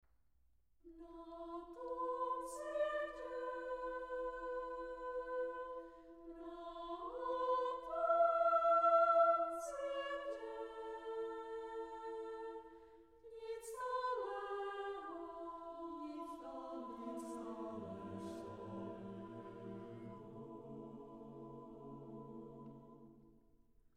natočeno v červnu 1997 ve studiu Domovina v Praze
Česká sborová tvorba na texty lidové poezie